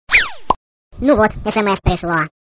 » Звук, смс пришло Размер: 12 кб